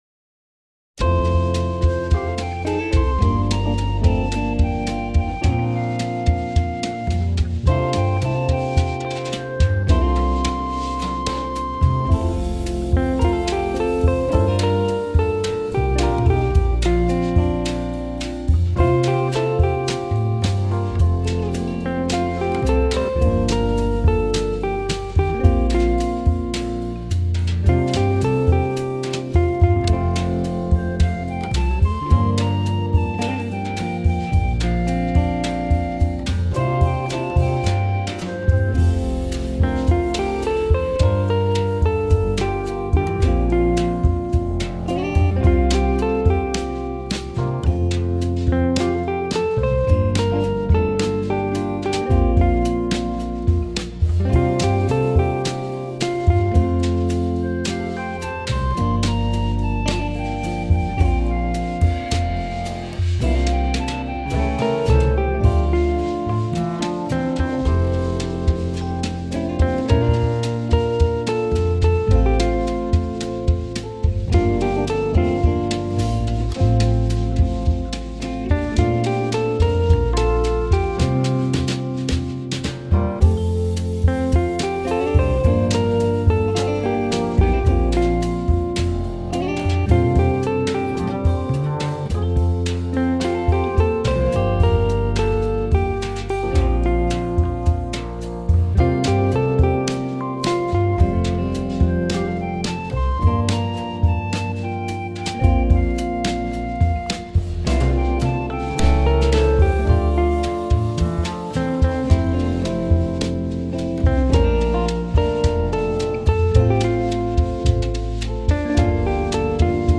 relaxed, nicely laid back